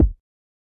CardiakKick3.wav